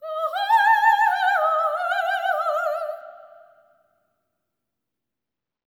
OPERATIC12.wav